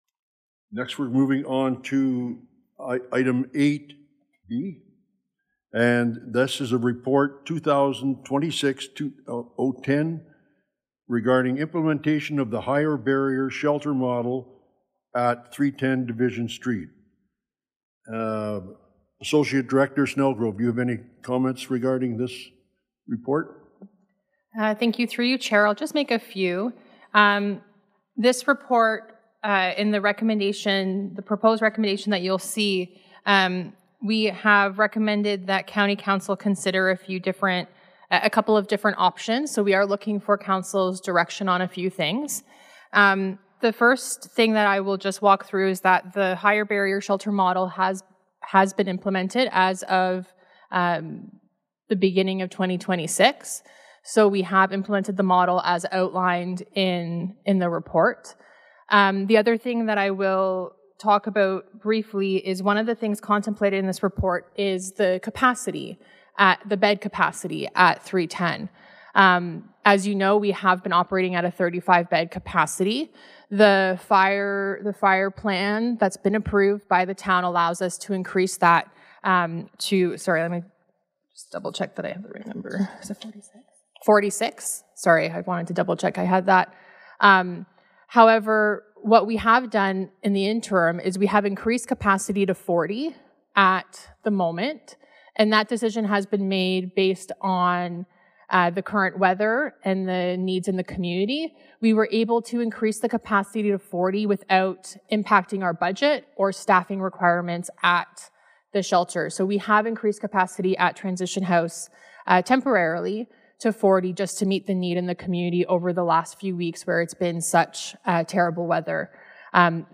Northumberland County Social Services Committee members were told that the possible addition of six beds to Transition House could cost an extra $252,000, including additional staff and support costs, at the Jan. 7 social services committee meeting.
In Their Words is a feature that allows people to listen in on what is happening at the various councils in Northumberland.